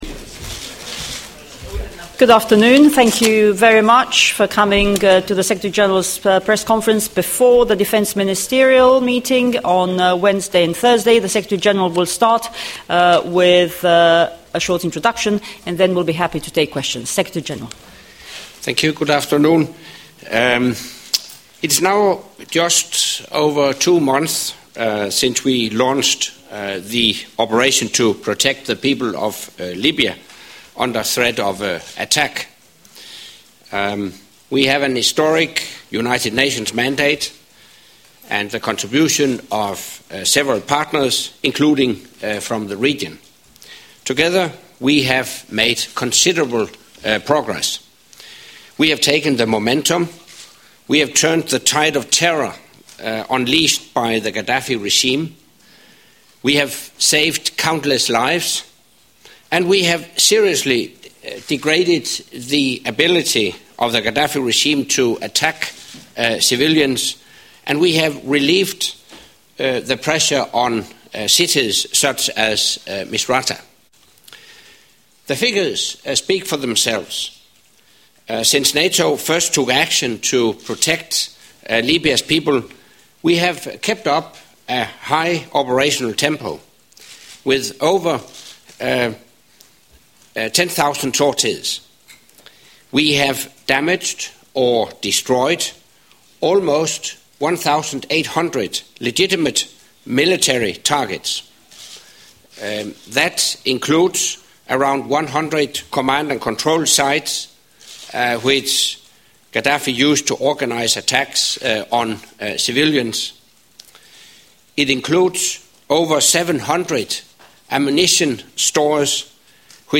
Пресс-конференция генерального секретаря НАТО
6 июня 2011 г. Генеральный секретарь НАТО Андерс Фог Расмуссен провел свою регулярную ежемесячную пресс-конференцию.
Monthly press briefing by NATO Secretary General Anders Fogh Rasmussen at NATO HQ